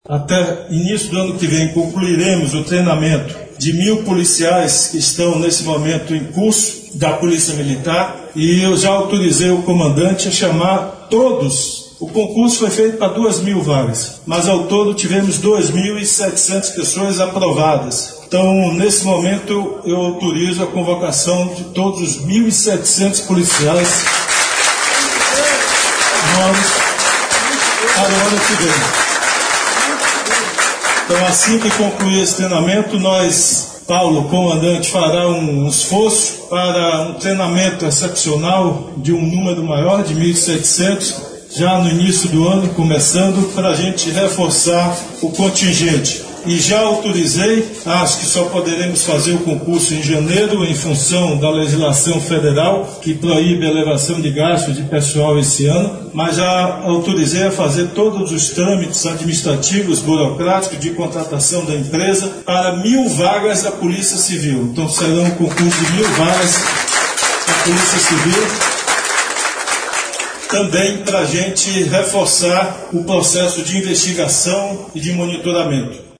O anúncio foi feito pelo governador Rui Costa, nesta segunda-feira (277), durante a entrega de novas viaturas para a PM. Ele contou que está sendo concluído o treinamento de mil policiais e será feito um esforço para que um número maior de profissionais seja preparado. Rui comentou ainda sobre o novo concurso para a Polícia Civil, que deve ser lançado a partir de janeiro.